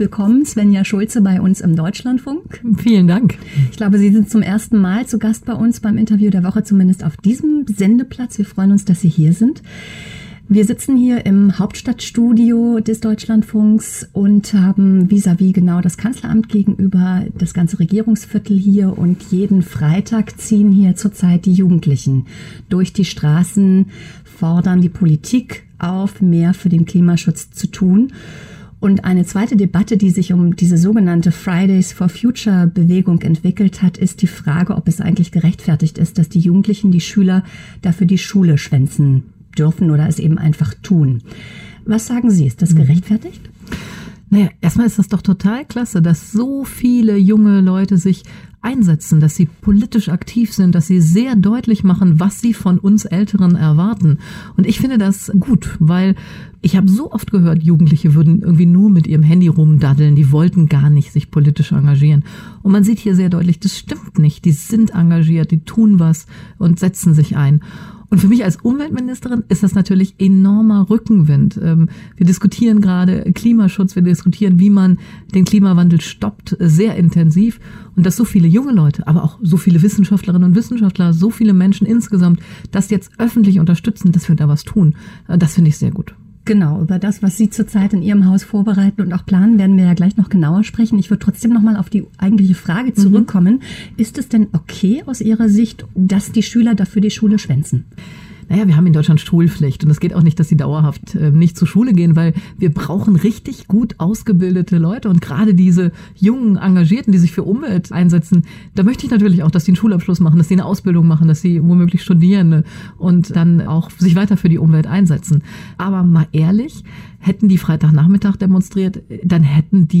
Hören Sie das Interview des Dlf vom 24.3.2019 komplett.